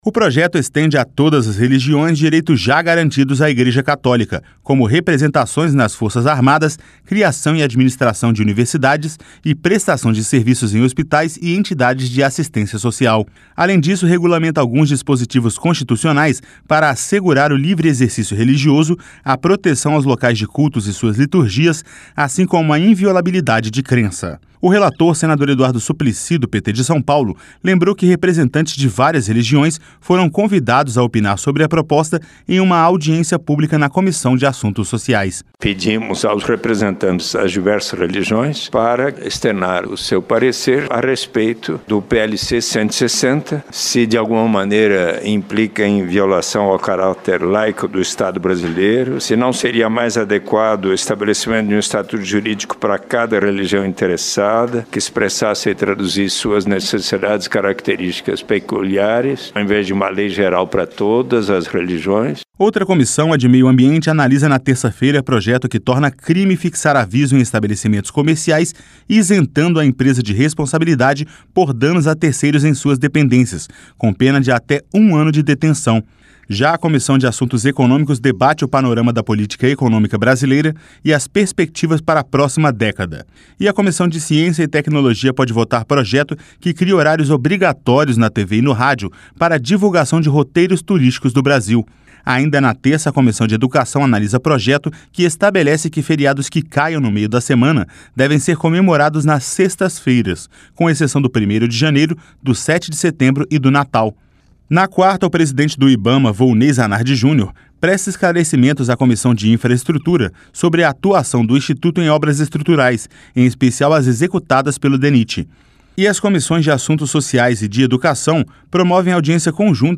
(Eduardo Suplicy) Pedimos aos representantes das diversas religiões para externar o seu parecer a respeito do PLC 160.